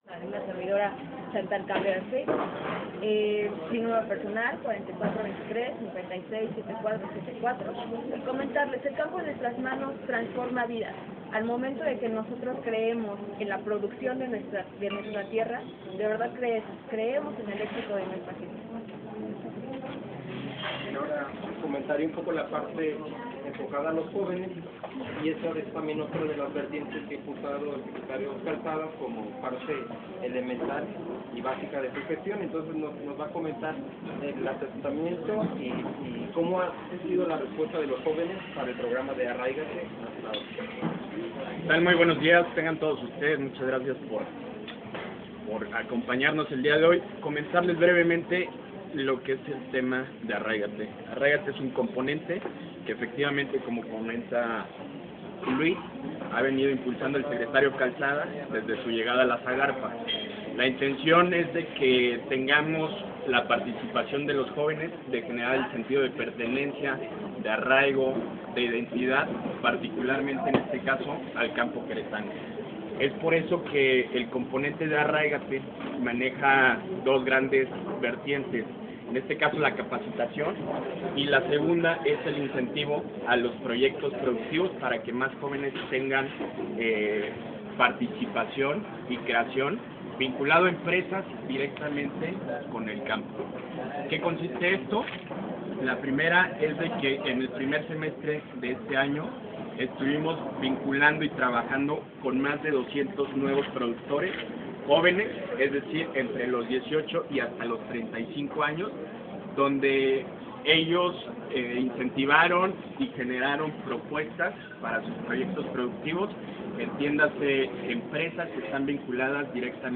Rueda de Prensa SAGARPA I